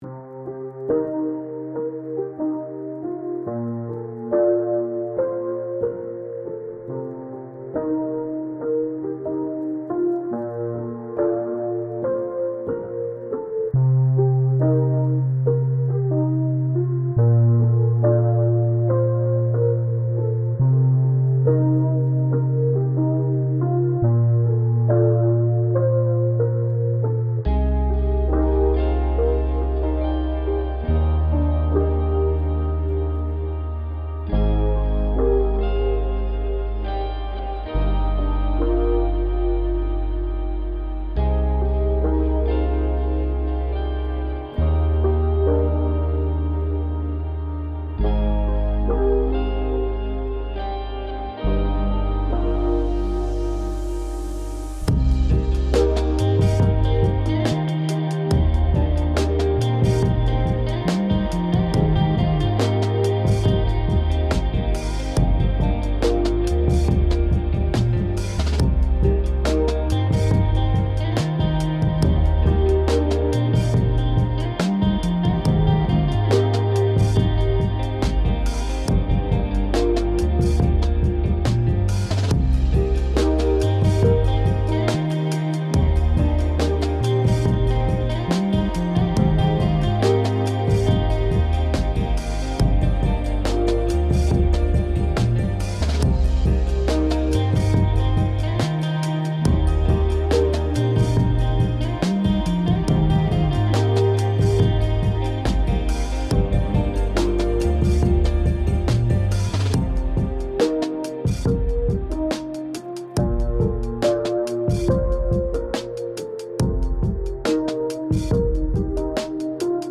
70 Bpm – Chill Melancholy Lofi